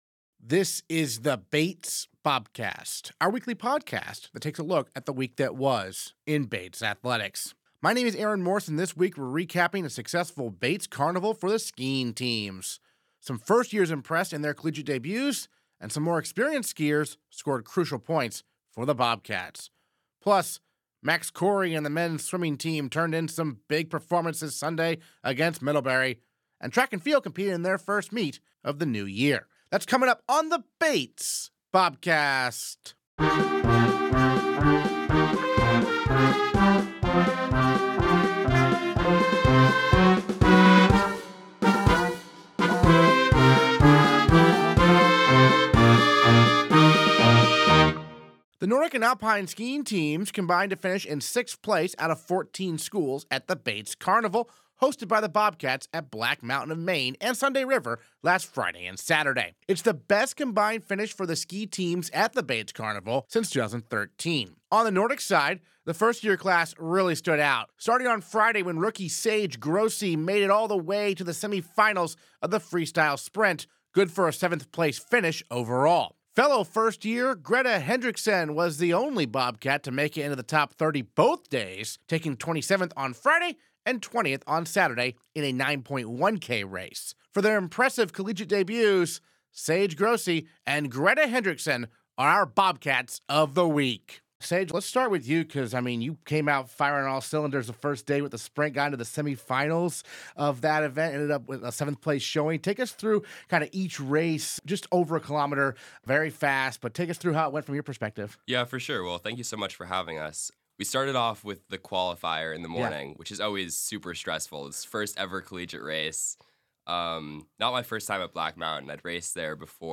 Interviews this episode: